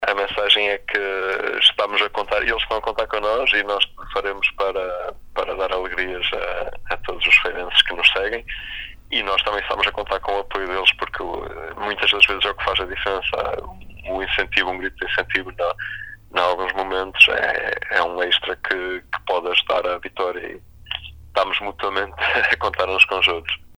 Em entrevista à Sintonia